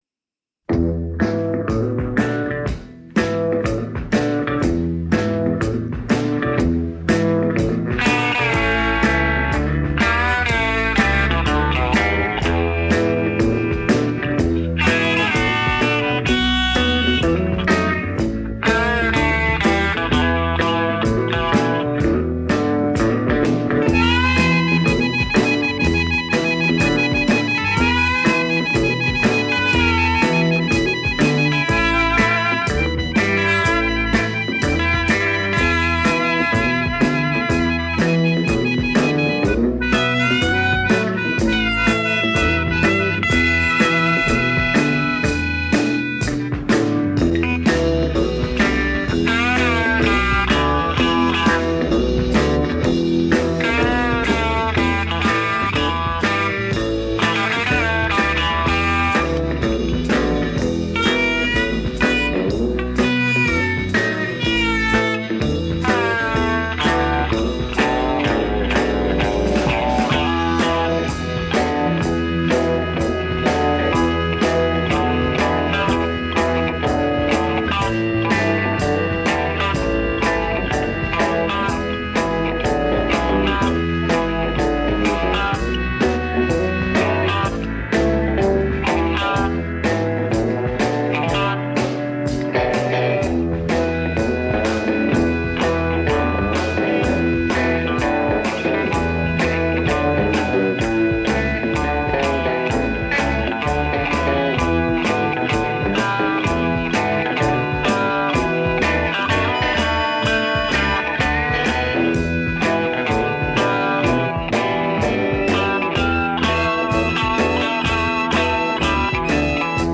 инструментальная композиция
Запись состоялась 4 ноября 1965 года в студии «Эбби Роуд».